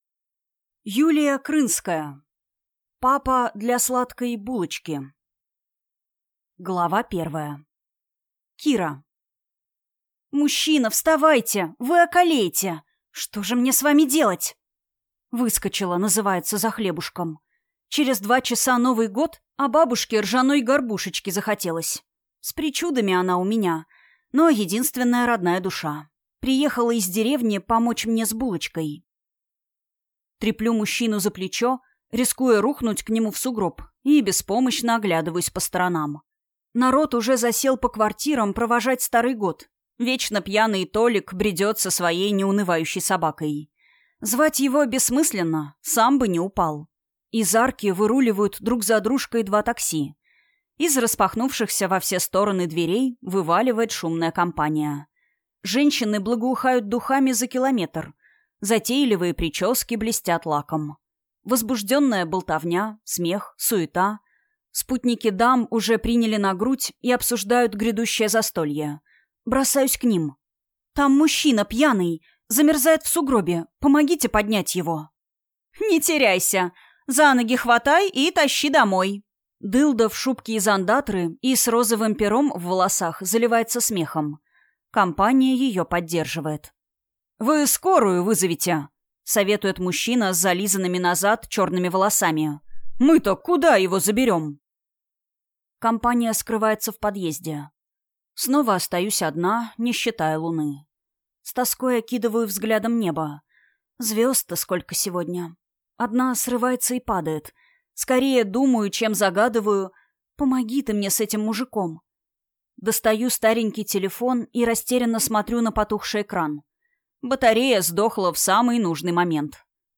Аудиокнига Папа для сладкой Булочки | Библиотека аудиокниг